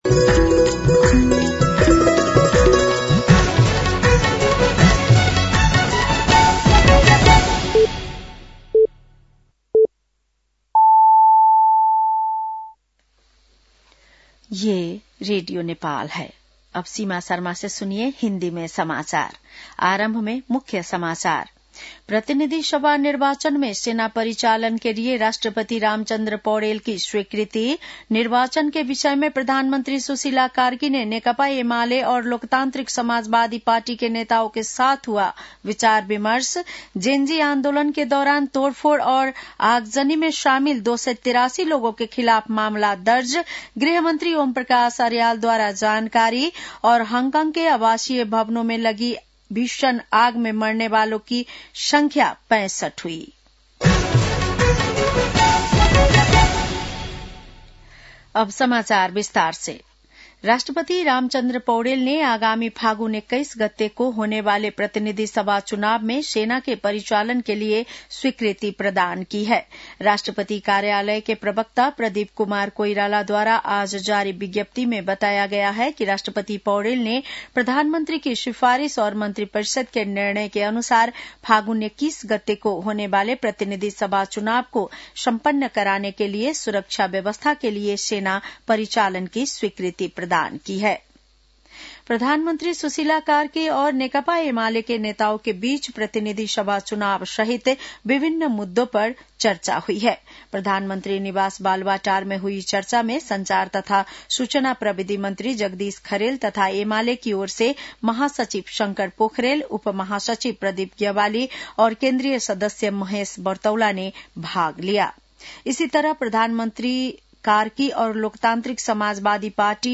बेलुकी १० बजेको हिन्दी समाचार : ११ मंसिर , २०८२
10-PM-Hindi-NEWS-8-11.mp3